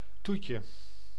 Ääntäminen
Synonyymit dessert nagerecht toespijs Ääntäminen Tuntematon aksentti: IPA: /ˈtutjə/ Haettu sana löytyi näillä lähdekielillä: hollanti Käännös Ääninäyte Substantiivit 1. dessert US Suku: n .